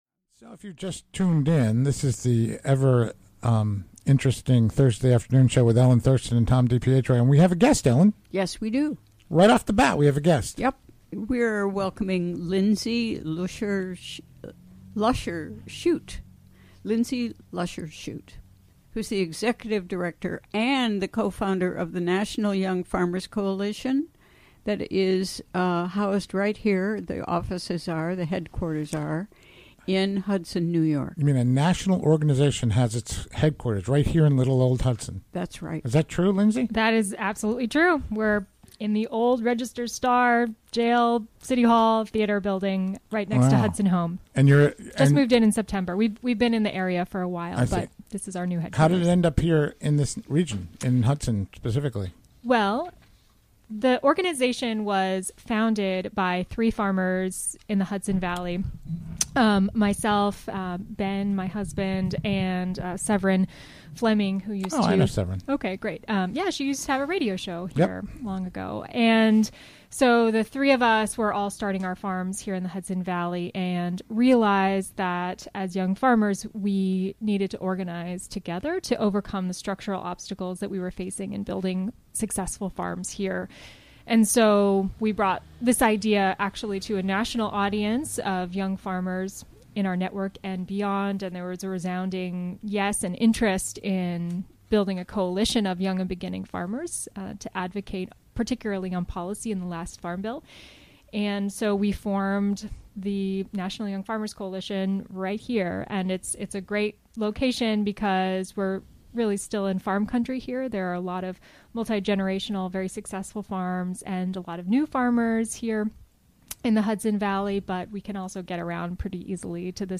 Recorded during the WGXC Afternoon Show on Thu., Dec. 7, 2017.